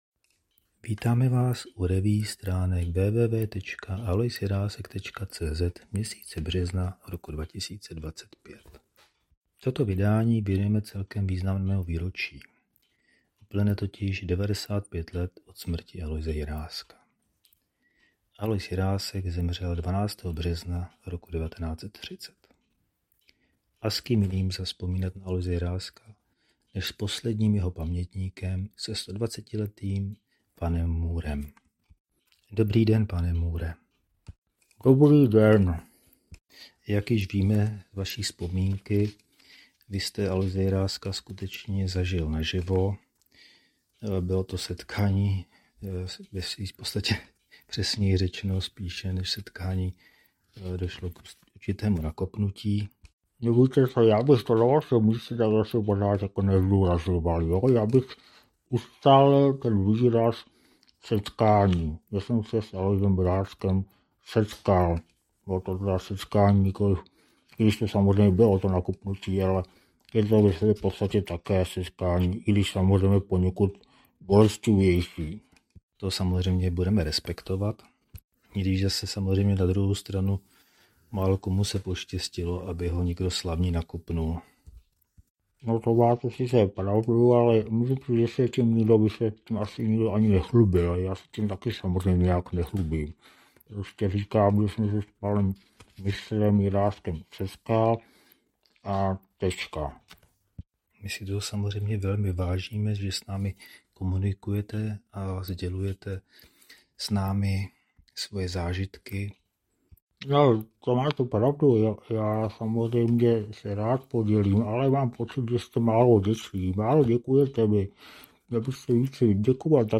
Další pokus o kombinovanou revue je s obvyklou humornou nadsázkou věnován významnému výročí – 12.března 1930, před 95 lety, zemřel v Praze Alois Jirásek.